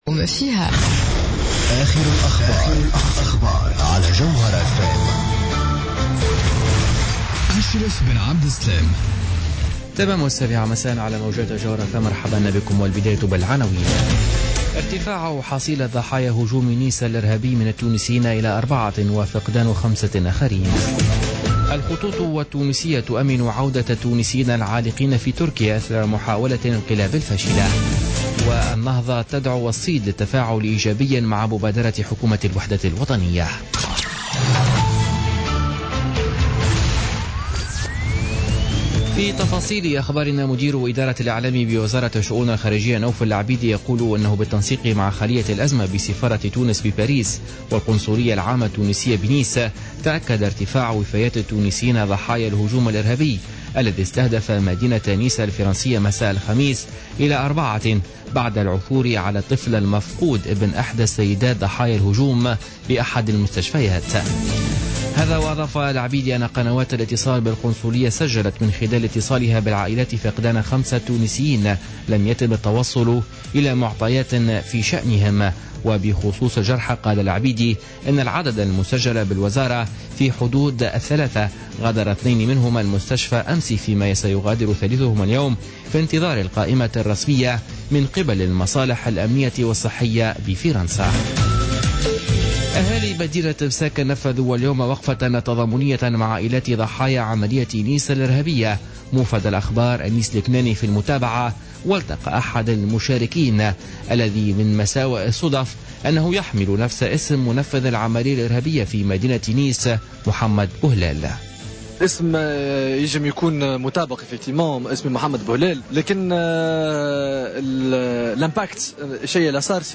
Journal Info 19h00 du samedi 16 juillet 2016